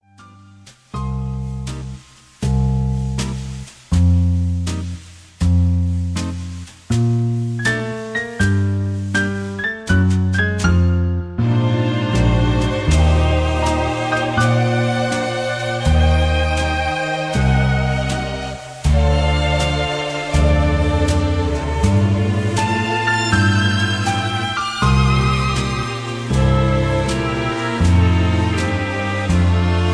Key-Bb) Karaoke MP3 Backing Tracks
Just Plain & Simply "GREAT MUSIC" (No Lyrics).